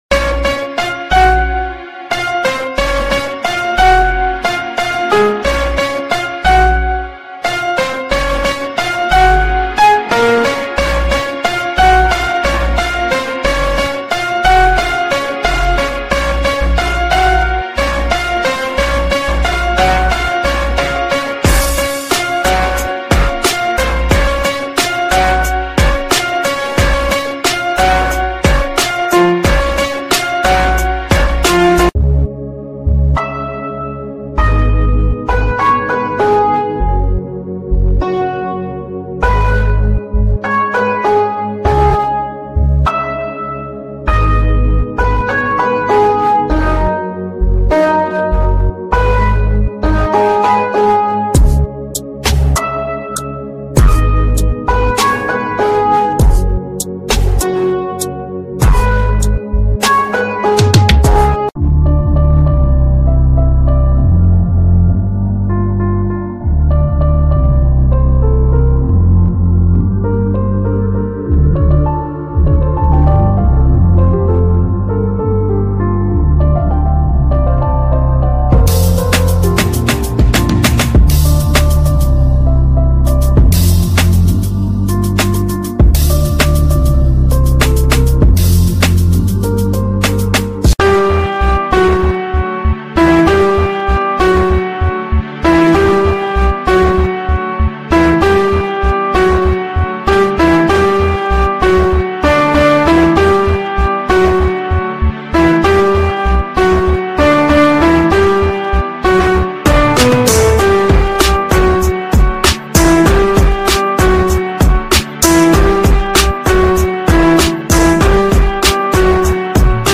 🇨🇦 Montreal Walking Tour sound effects free download
Street Life in Downtown Montreal